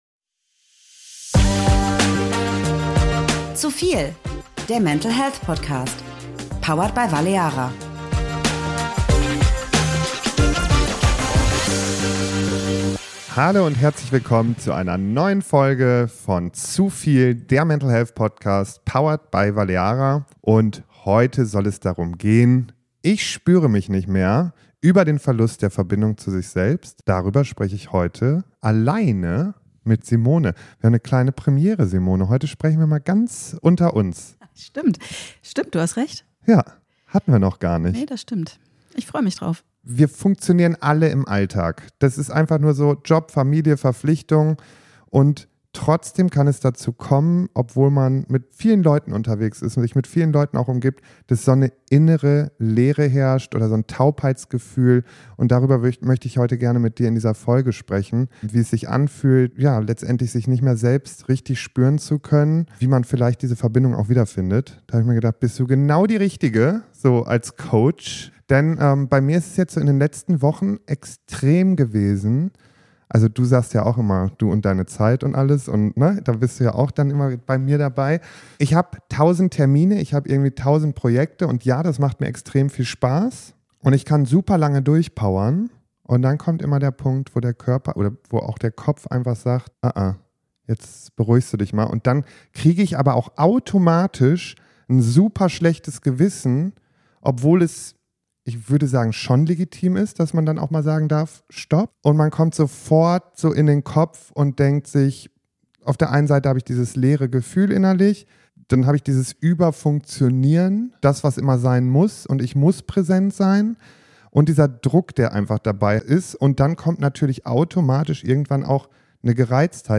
Eine ehrliche, ruhige Folge über innere Leere, Achtsamkeit und den Weg zurück zu sich selbst.